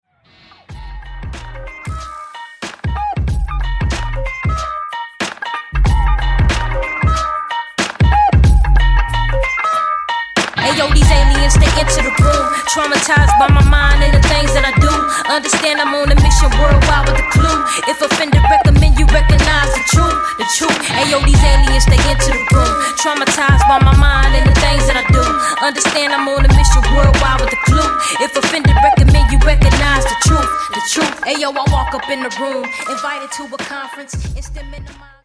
grew from a killer beat
a young female MC